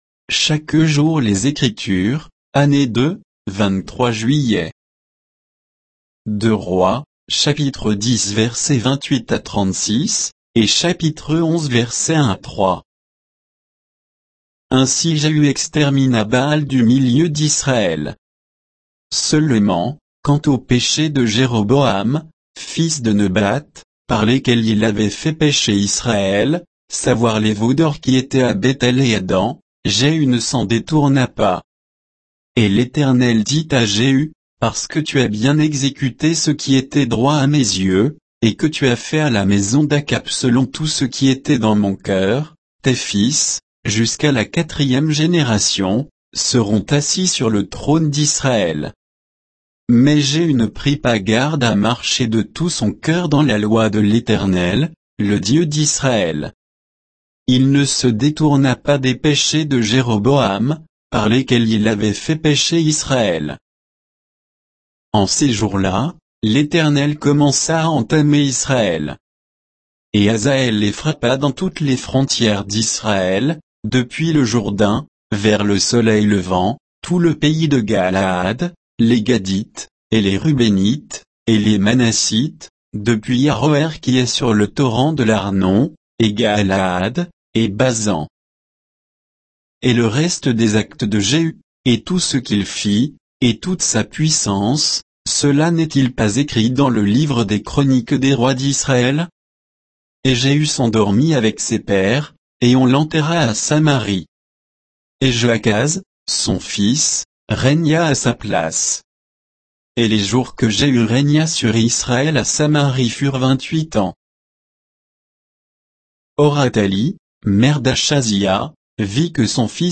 Méditation quoditienne de Chaque jour les Écritures sur 2 Rois 10, 28 à 11, 3